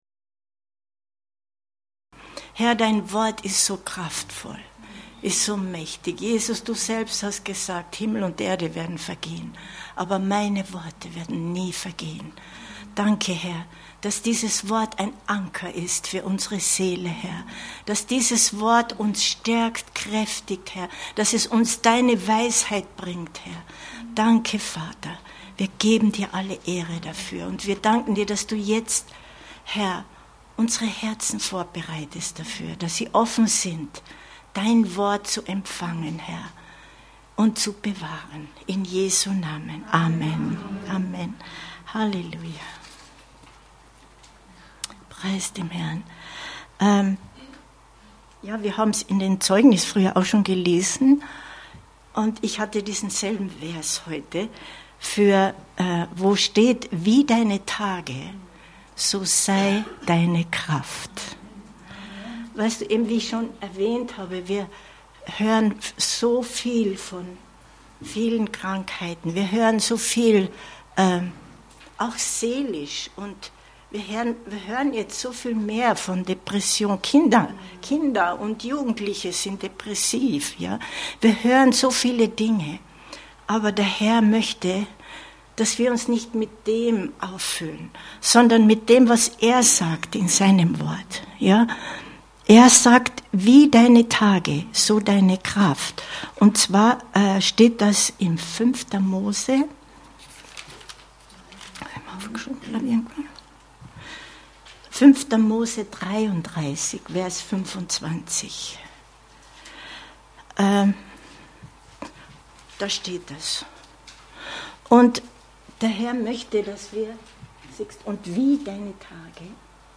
so sei deine Kraft! 30.07.2023 Predigt herunterladen